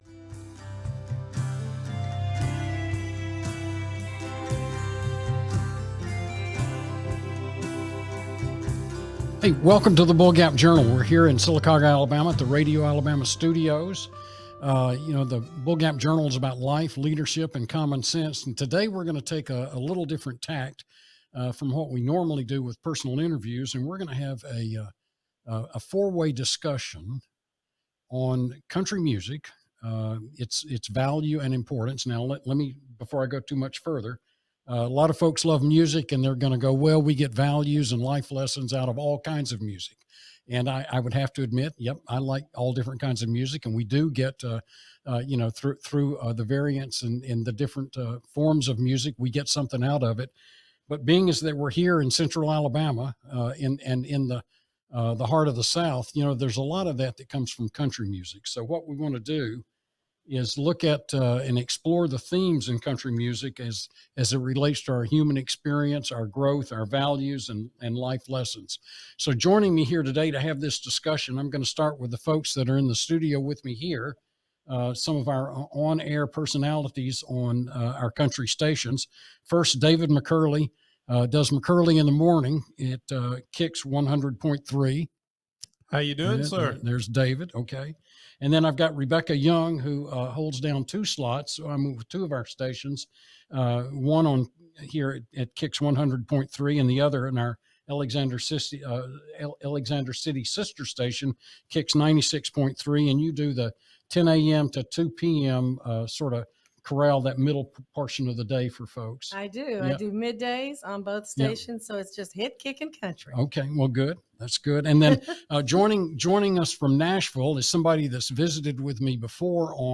Episode 22 - Country Music Roundtable